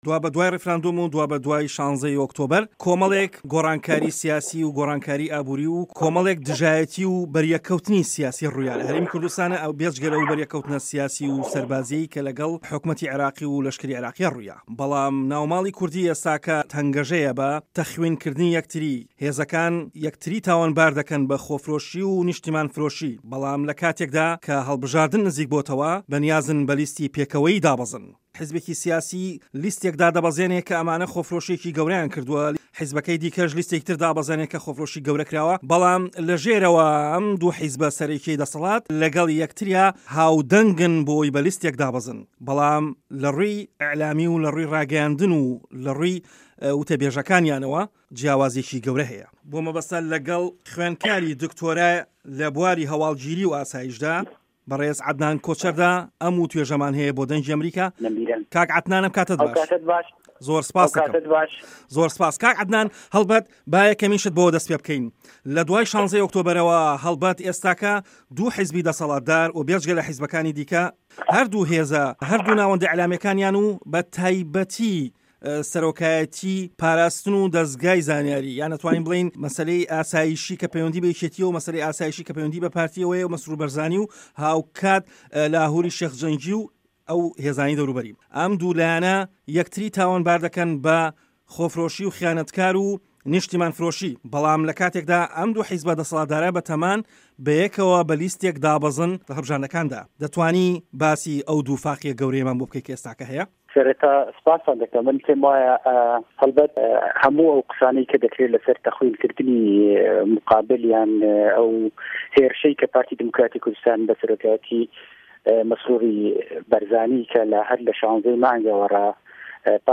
گفتووگۆ